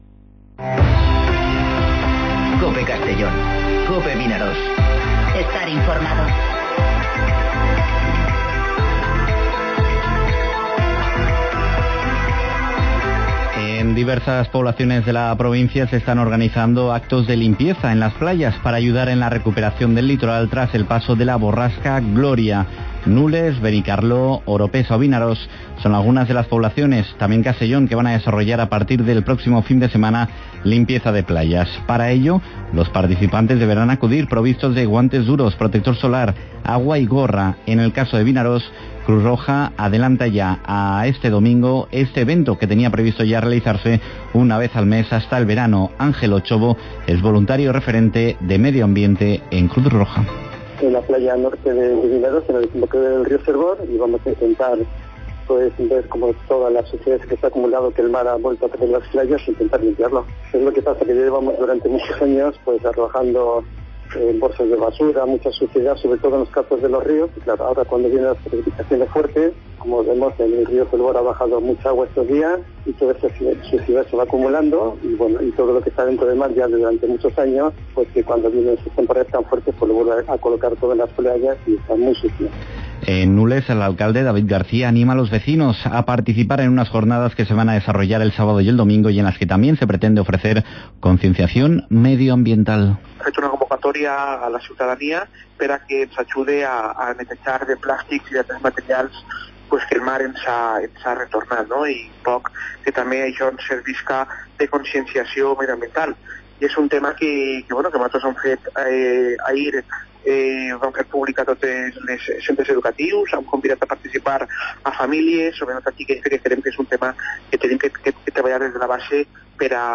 Informativo Mediodía COPE en Castellón (29/01/2020)